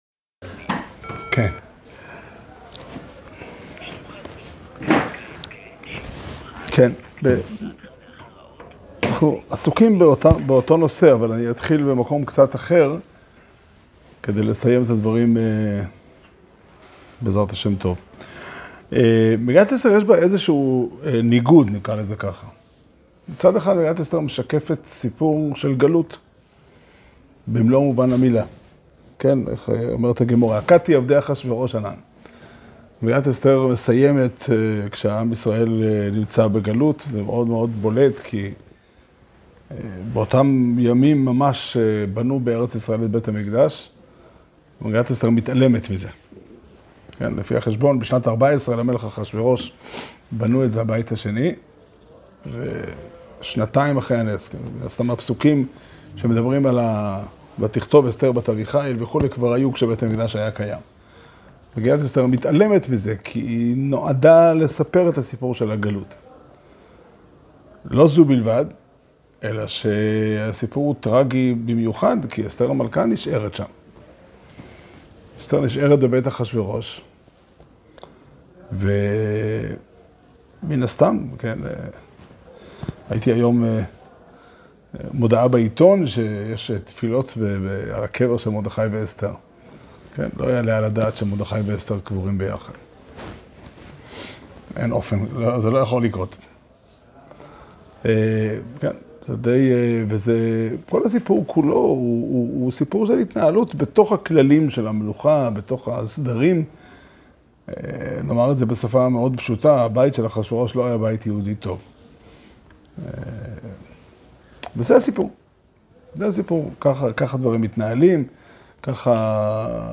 שיעור שנמסר בבית המדרש פתחי עולם בתאריך ט' אדר ב' תשפ"ד